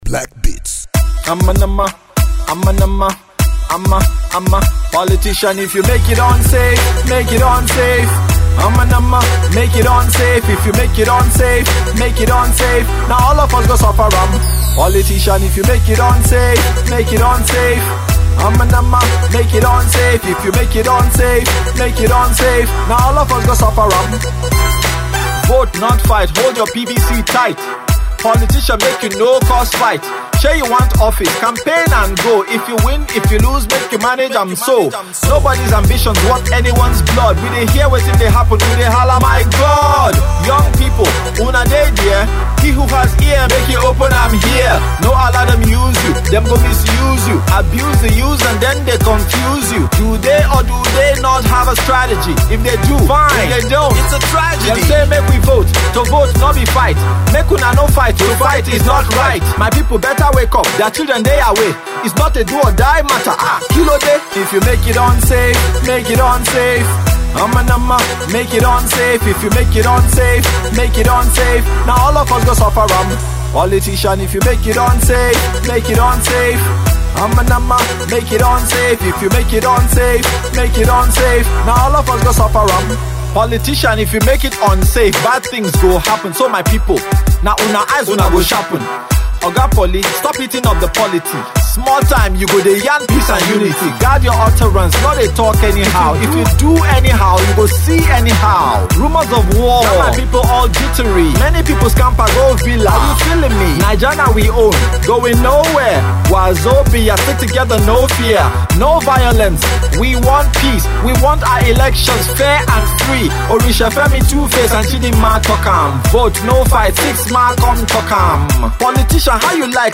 Hip-Hop
Its a Solid Rap song